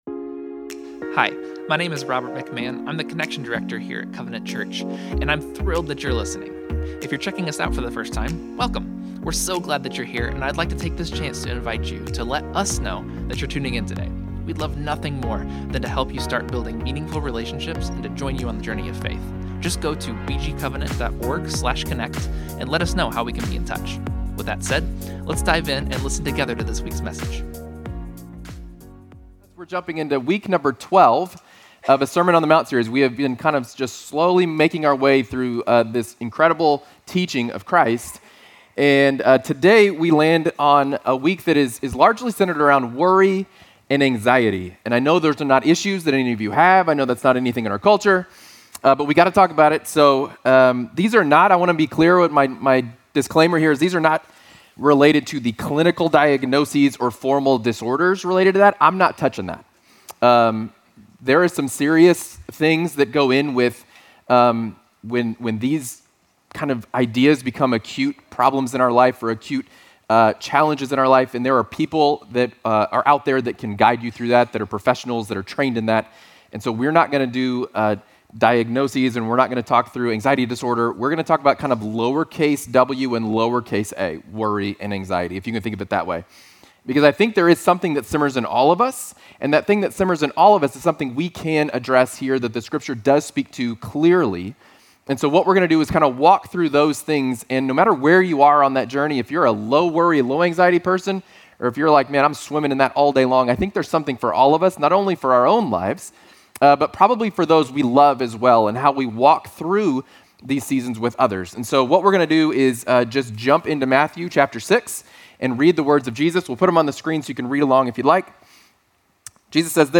Join us for this sermon series, Then Sings My Soul, where we unpack the richness of these ancient hymns and apply them to our daily lives.